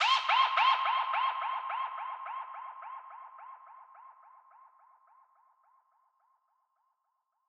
[MAMA] -  VOX.wav